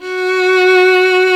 Index of /90_sSampleCDs/Roland - String Master Series/STR_Violin 1-3vb/STR_Vln3 % + dyn
STR VLN3 F#3.wav